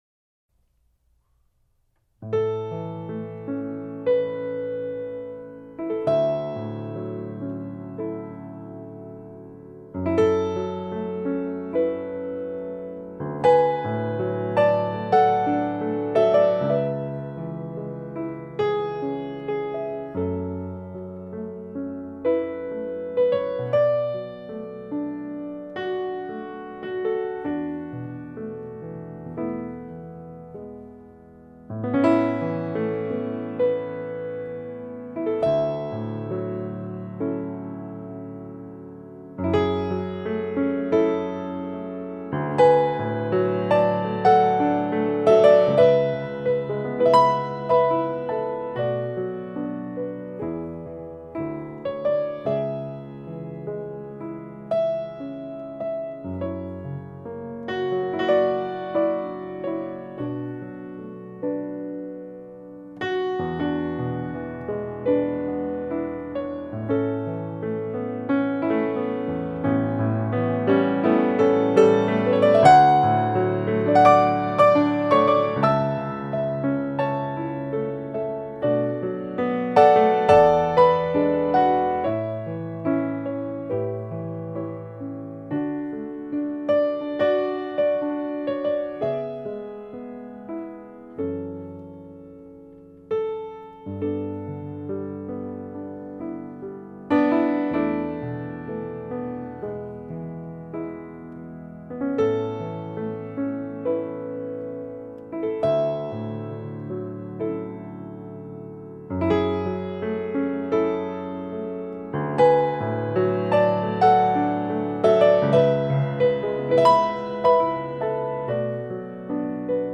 original piano music with a romantic touch